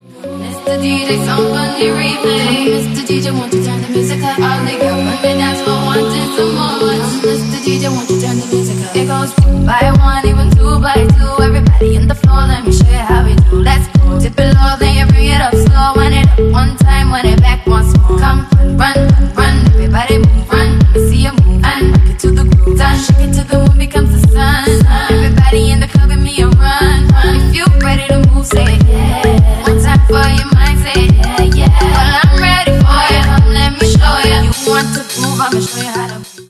Ремикс
тихие